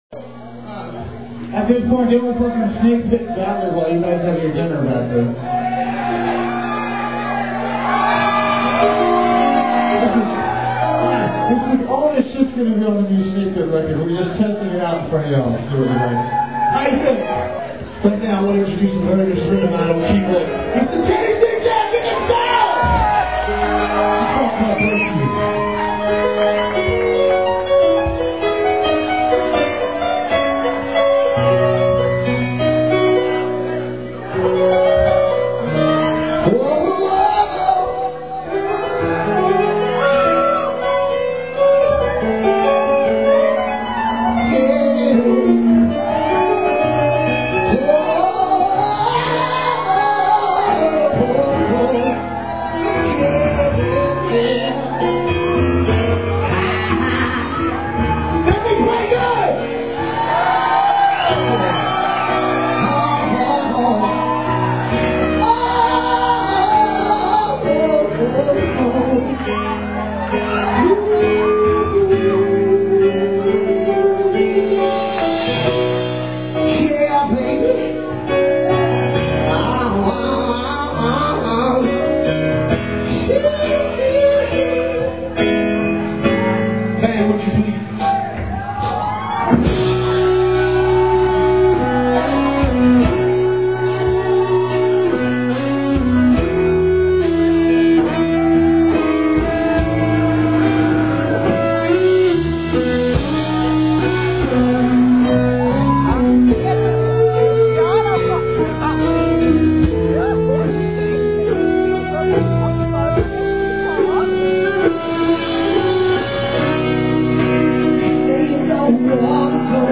Listen to the wonderful intro here.